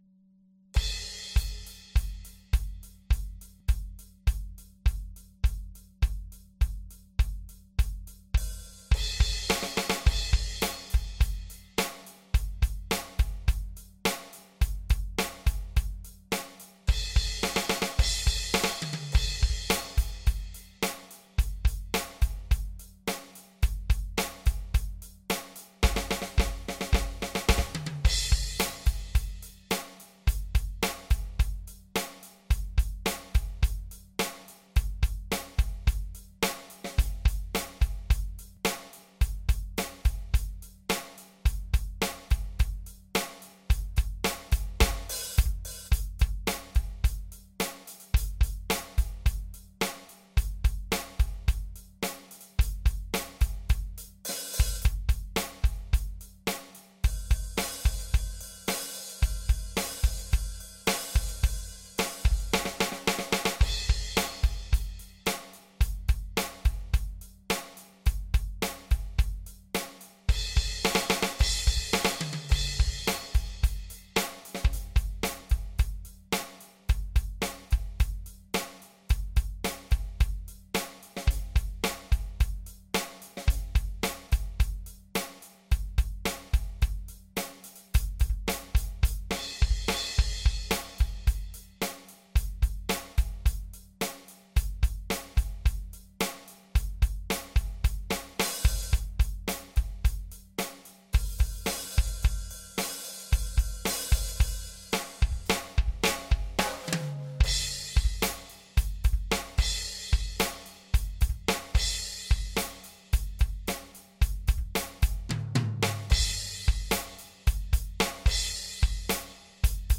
最初と最後でテンポが違う。
これは6回目のテイク。